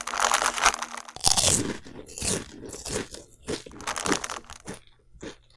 action_eat_chips_0.ogg